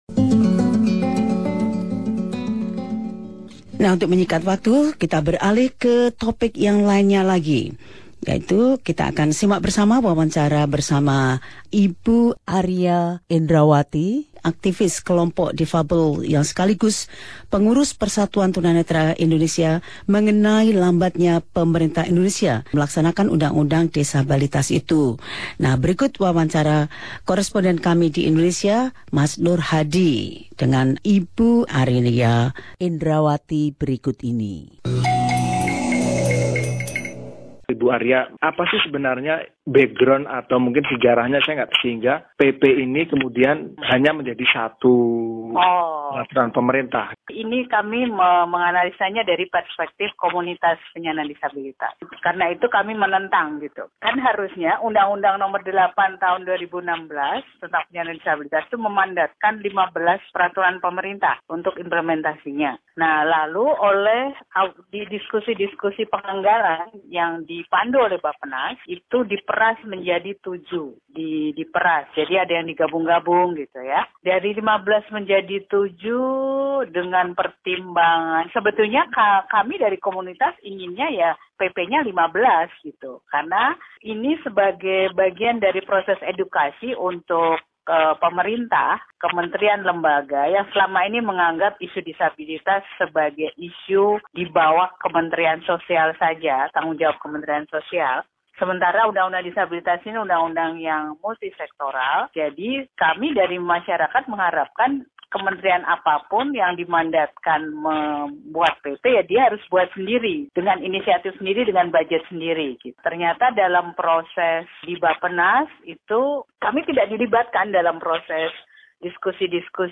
wawancaranya dengan koresponden Radio SBS Program Bahasa Indonesia